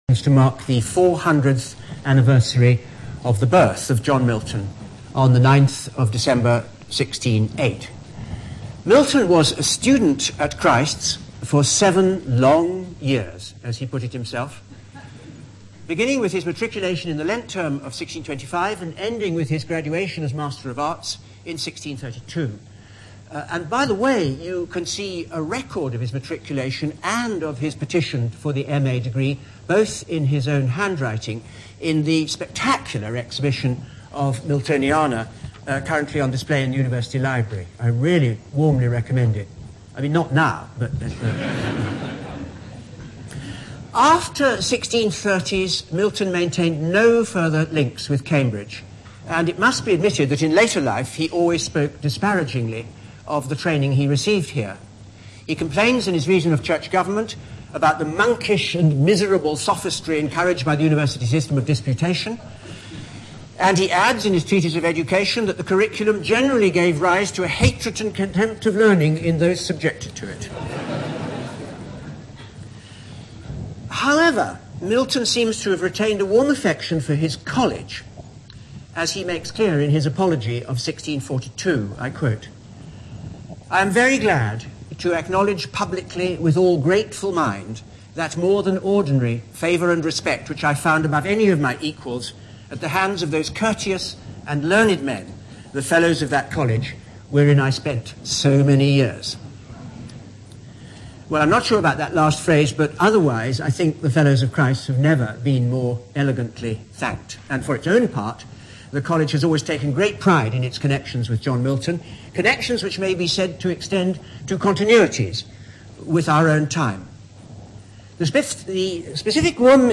Quentin Skinner's lecture, given on 30 January 2008, can be listened to here.